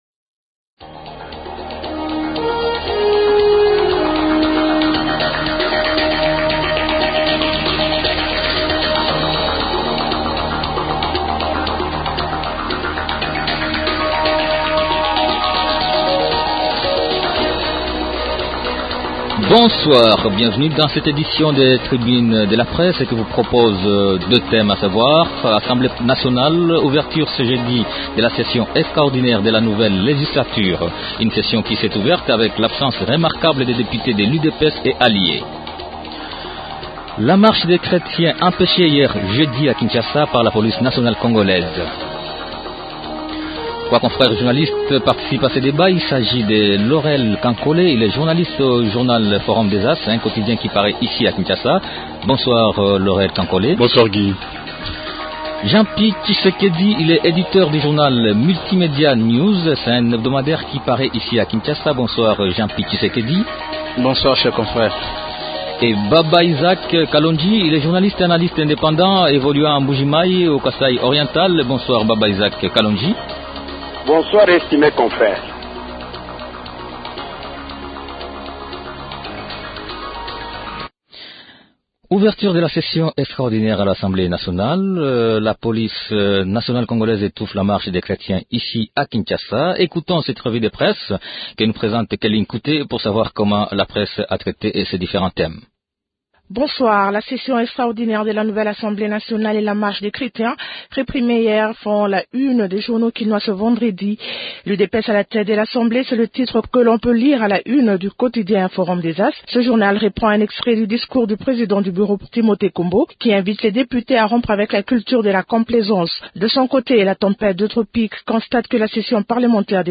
Deux thèmes à débattre dans la tribune :